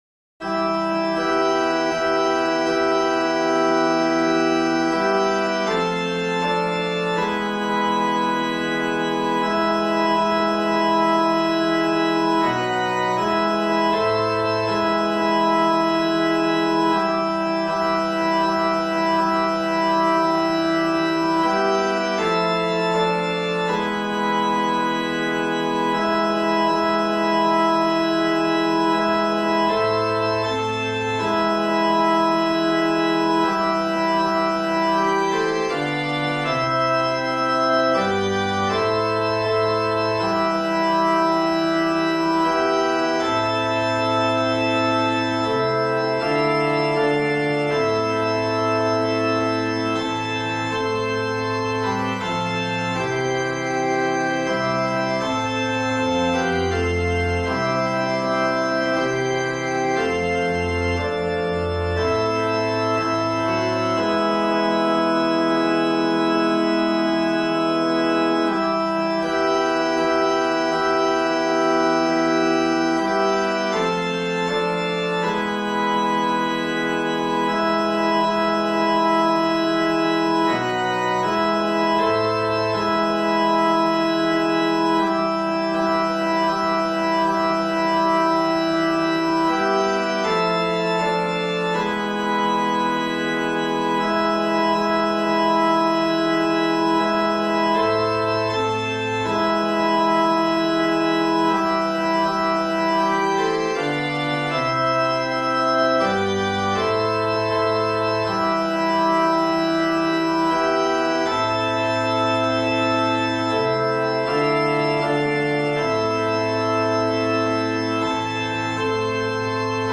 Play Third Mode Melody (for keyboard), print the Sheet Music, download the MIDI file or